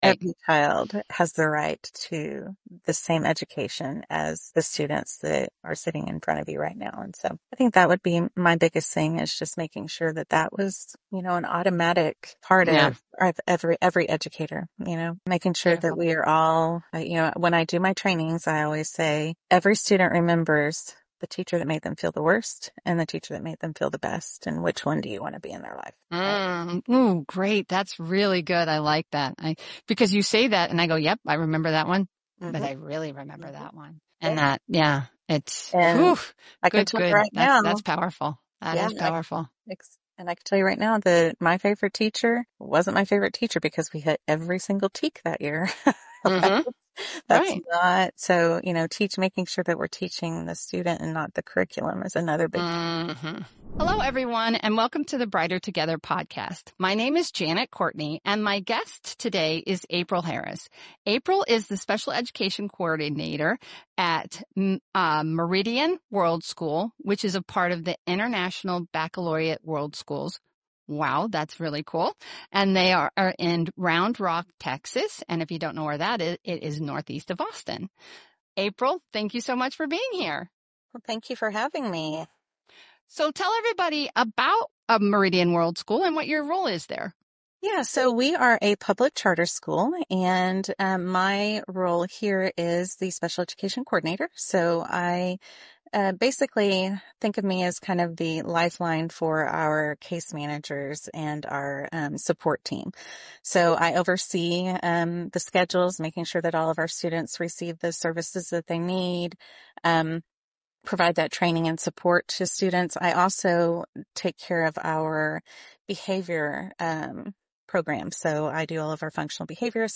In this episode of Brighter Together, we hear from a school leader who’s staying—and why.